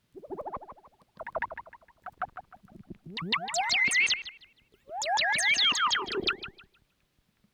Birds.wav